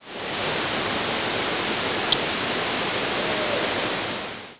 Canada Warbler nocturnal
presumed Canada Warbler nocturnal flight calls